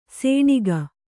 ♪ sēṇiga